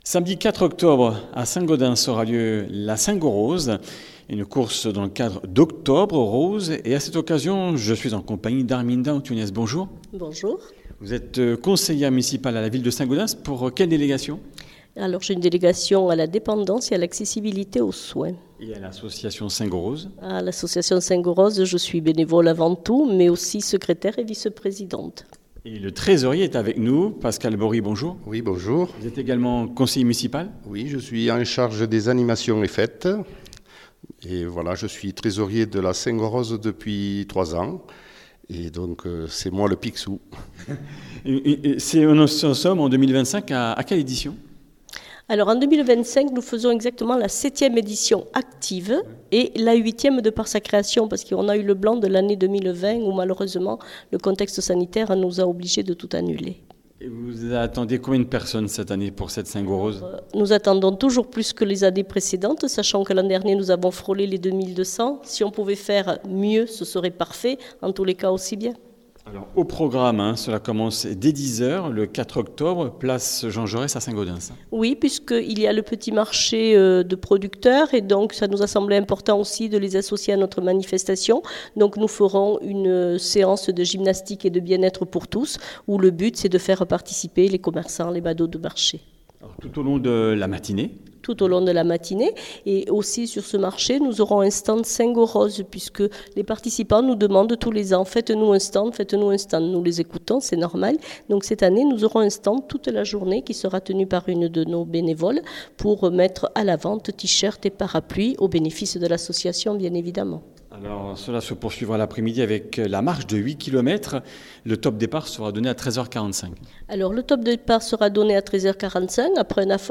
Comminges Interviews du 10 sept.
Une émission présentée par